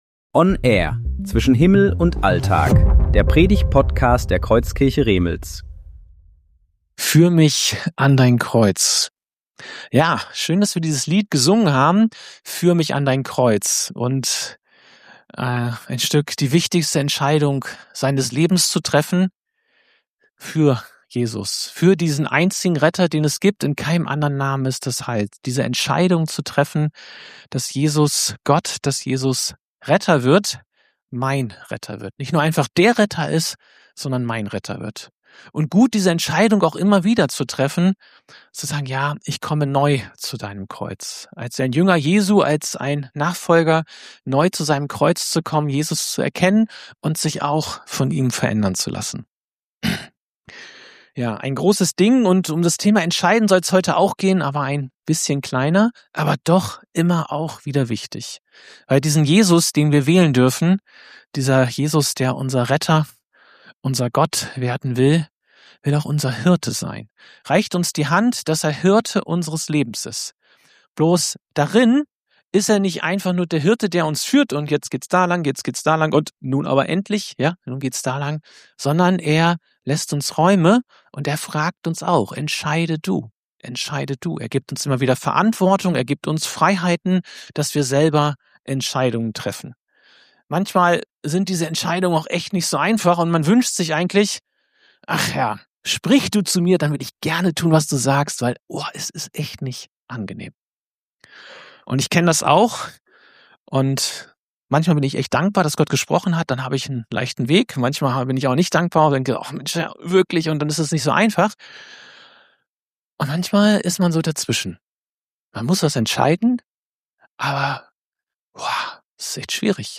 Predigtserie: Gottesdienst